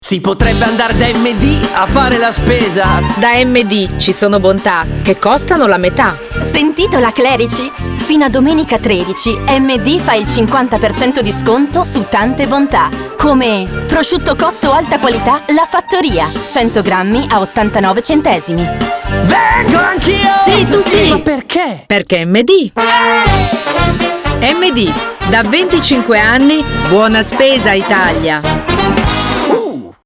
nuova campagna radio